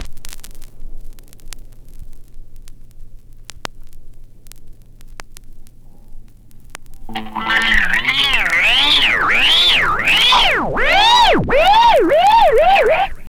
GTR1FX    -R.wav